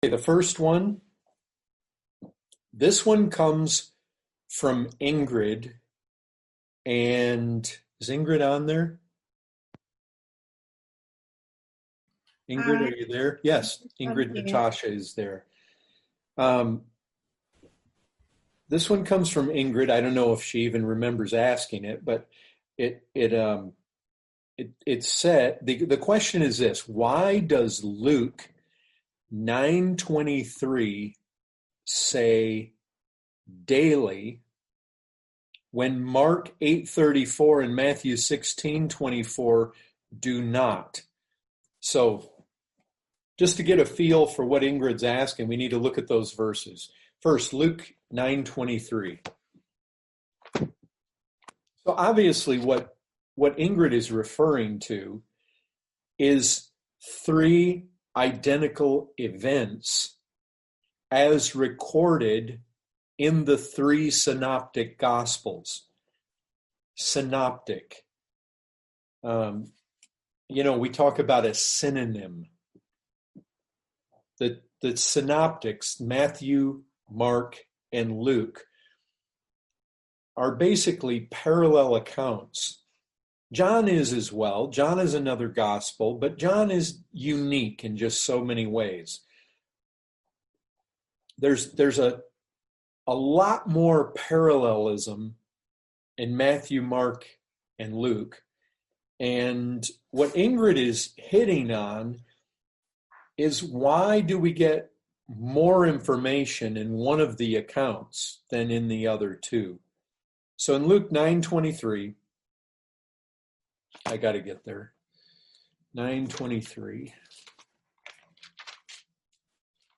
Questions & Answers